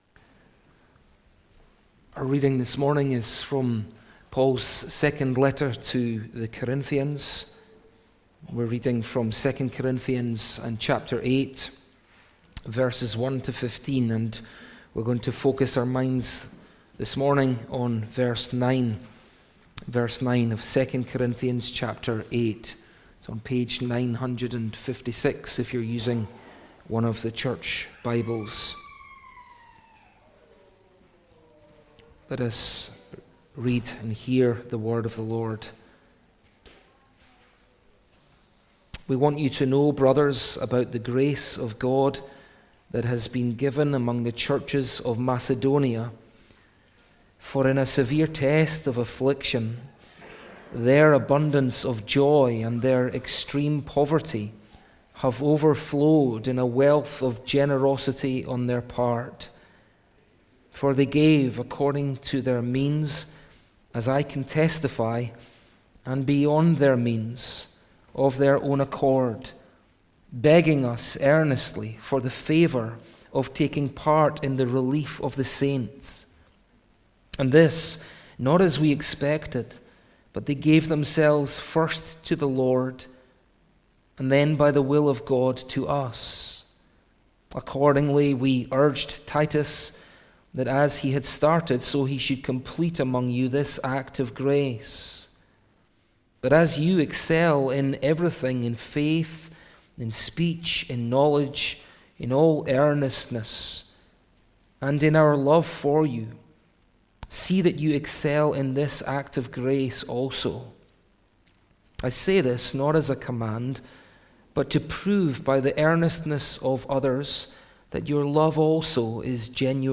He Who Was Rich Became Poor | SermonAudio Broadcaster is Live View the Live Stream Share this sermon Disabled by adblocker Copy URL Copied!